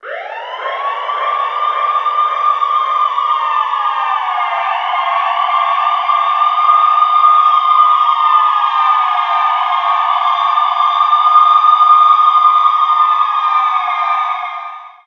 BIG WAIL2 -R.wav